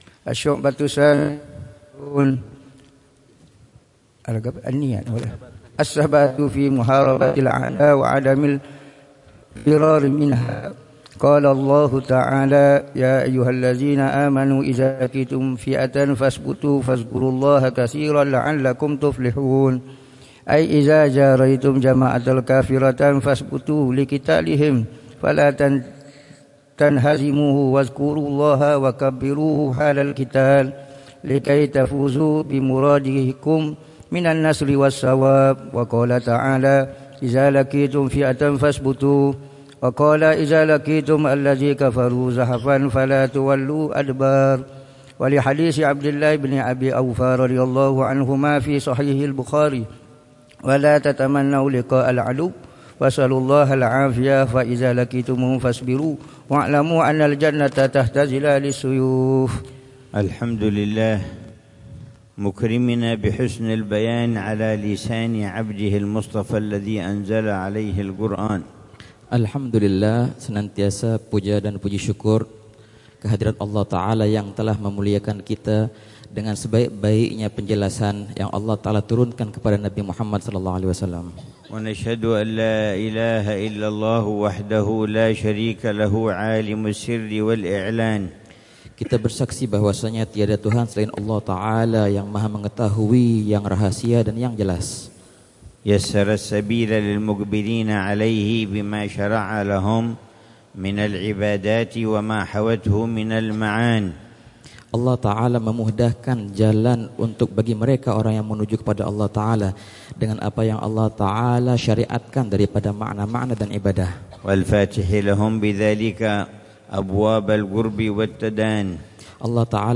الدرس التاسع للعلامة الحبيب عمر بن حفيظ في شرح كتاب: قامع الطغيان على منظومة شعب الإيمان، للعلامة محمد نووي بن عمر البنتني الجاوي